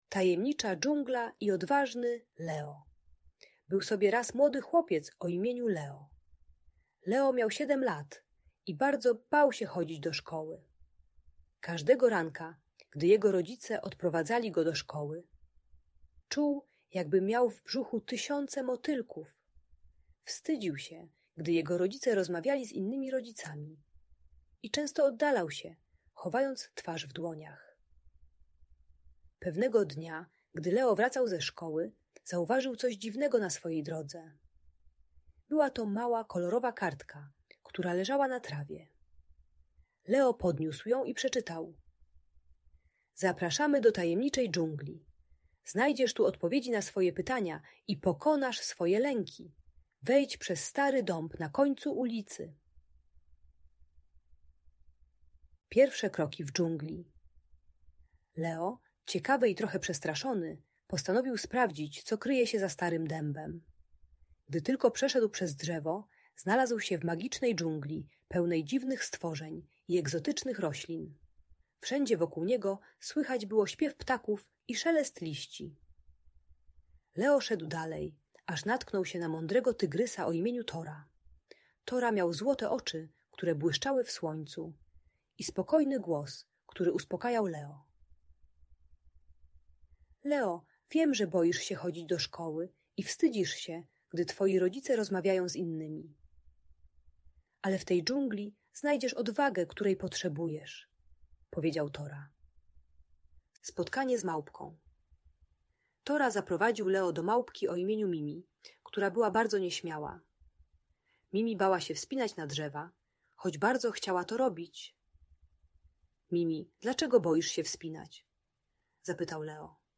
Tajemnicza Dżungla i Odważny Leo - Audiobajka